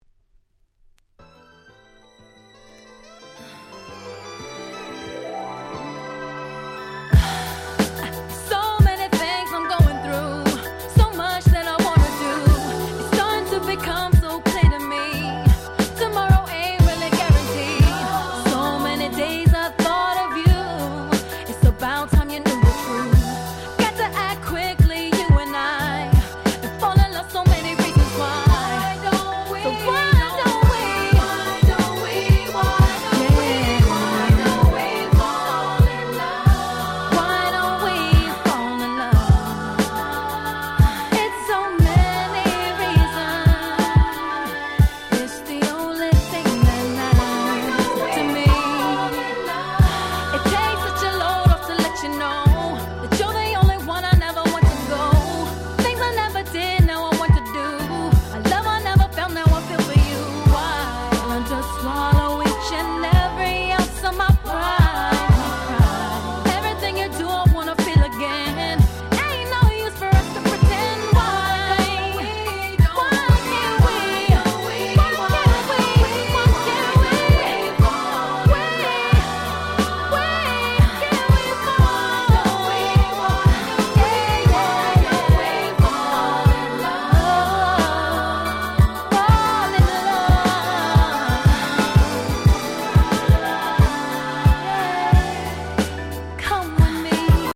02' Smash Hit R&B !!
何て言うんでしょ、この『夕暮れ感』、最高に気持ち良いです。